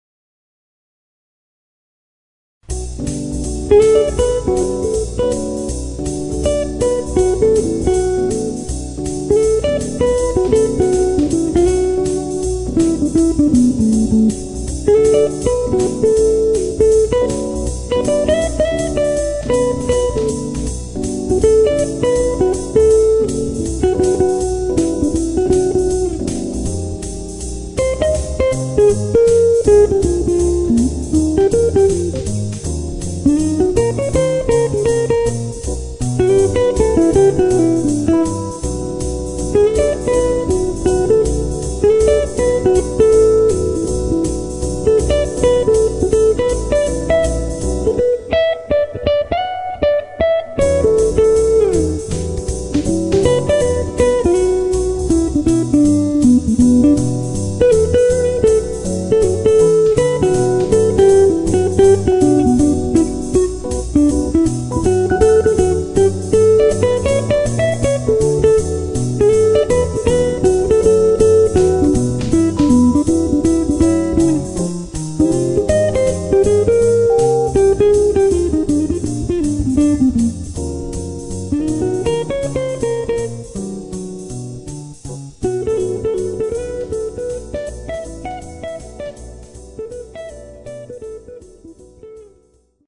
A lounge album with synthesised backings.
Nice guitar tone from my Gibson 335 on this one.